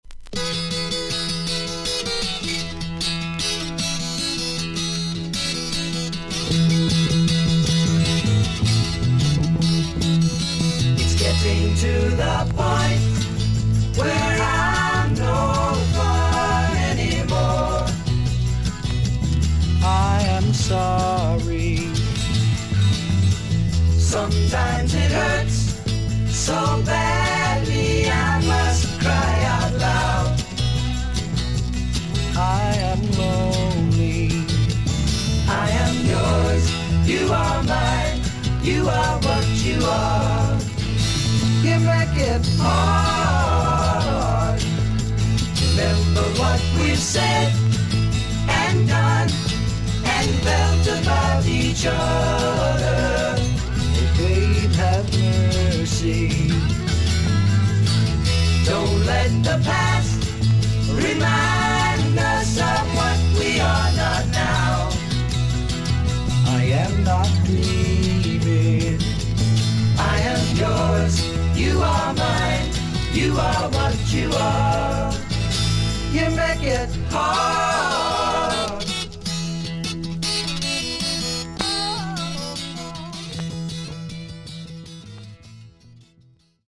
盤面に擦りキズ、小傷があり、全体的に大きめのサーフィス・ノイズあり。少々軽いパチノイズの箇所あり。